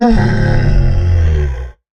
sounds / mob / camel / sit4.ogg